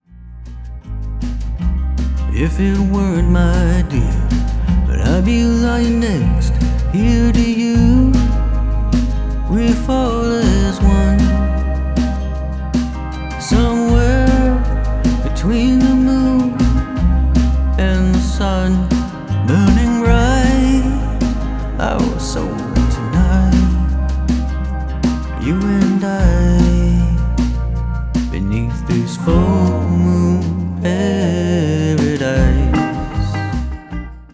Canadian Singer-Songwriter
GENRE : Americana